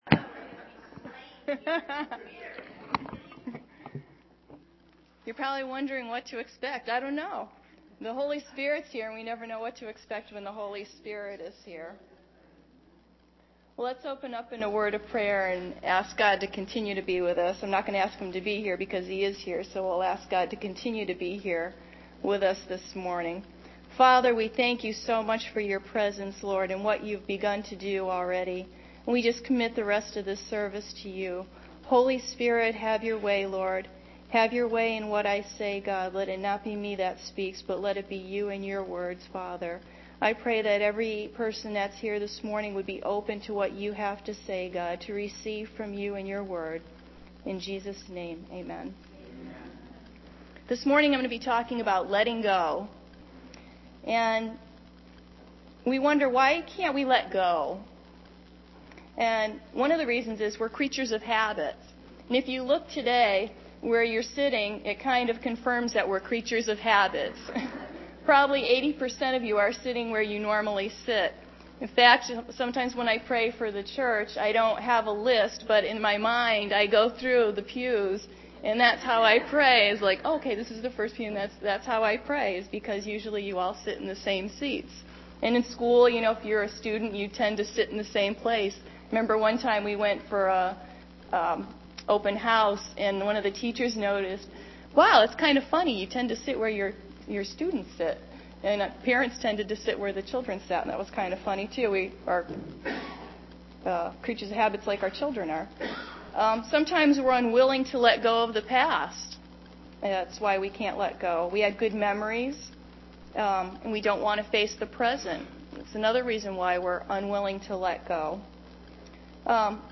Sunday November 11th – AM Sermon – Norwich Assembly of God